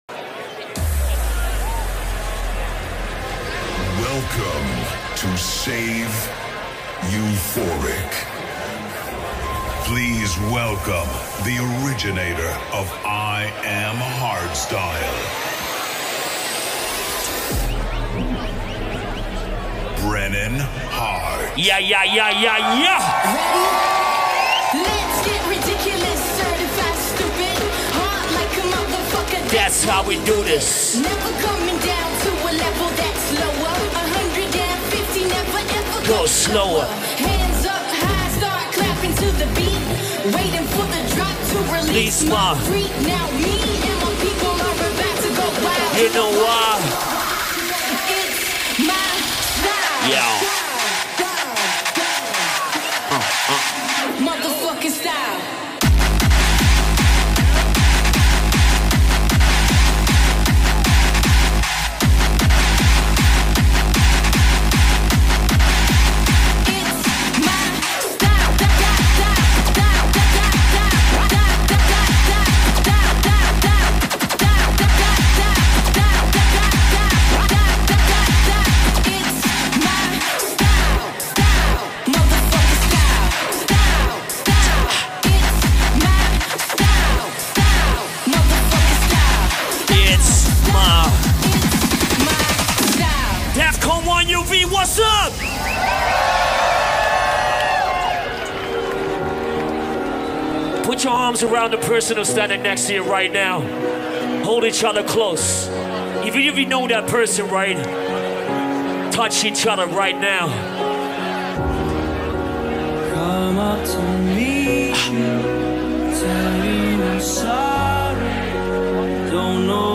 This liveset is embedded on this page from an open RSS feed.